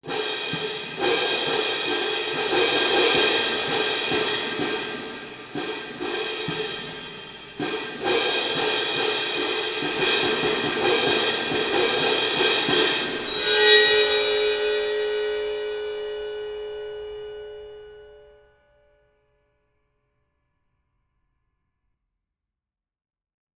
Talerze
Instrument o nieokreślonej wysokości dźwięku; zapis tylko rytmu
Talerze orkiestrowe uderzane są jeden o drugi na wysokości piersi uderzającego.
Dźwięki instrumentów są brzmieniem orientacyjnym, wygenerowanym w programach:
Talerze.mp3